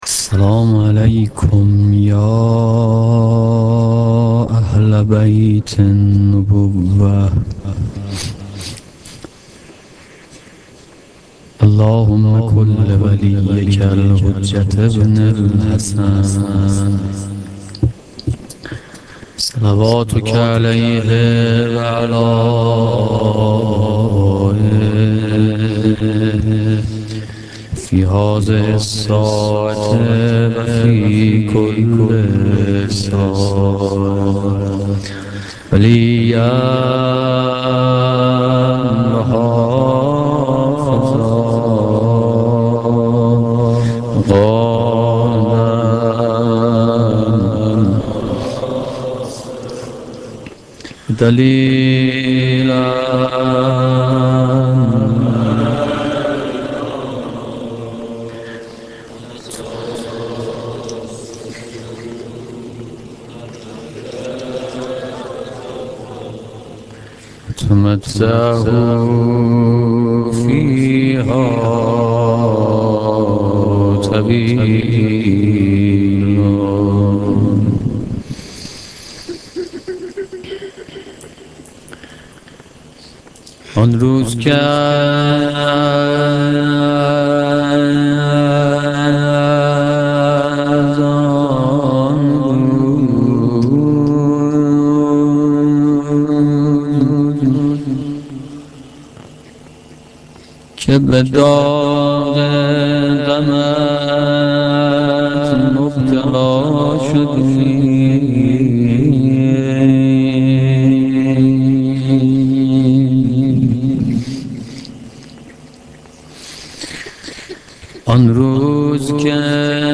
مداحی روضه شب هفتم